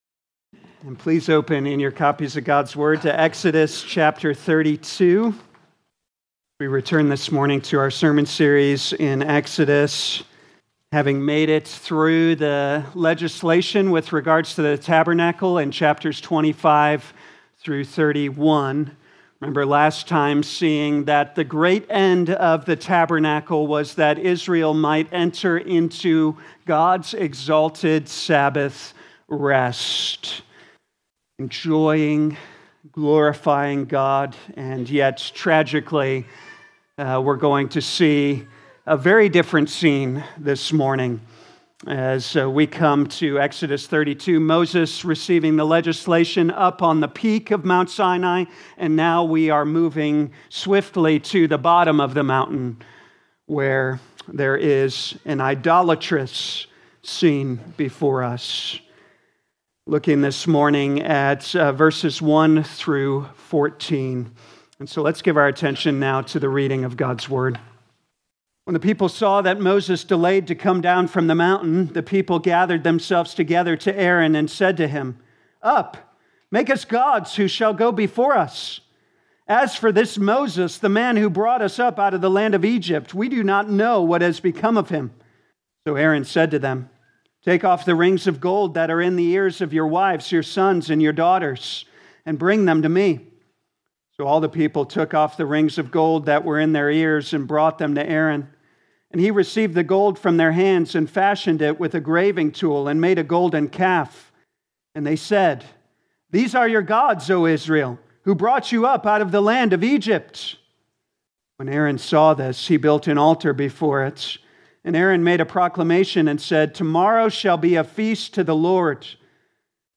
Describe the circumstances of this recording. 2026 Exodus Morning Service Download